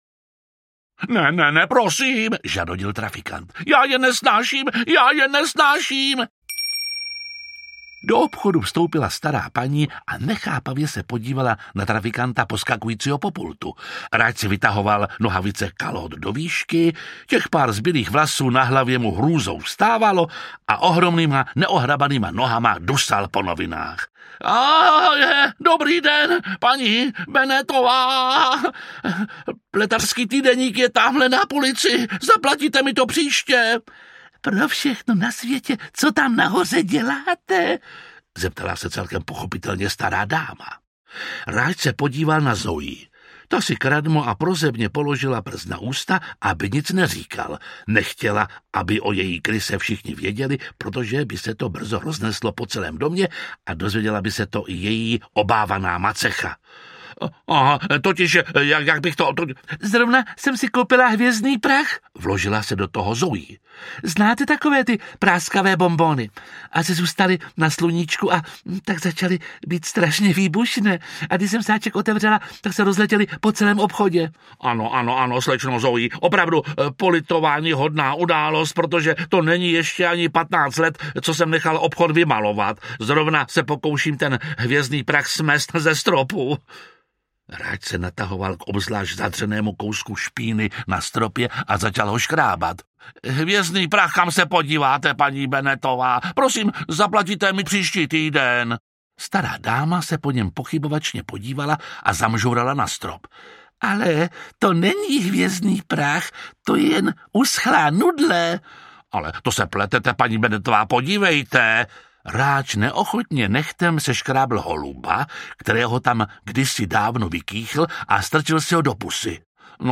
Krysburger audiokniha
Ukázka z knihy
Čte Jiří Lábus.
Vyrobilo studio Soundguru.
• InterpretJiří Lábus